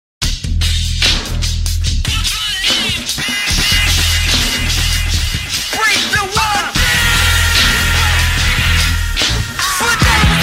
Play, download and share break wall original sound button!!!!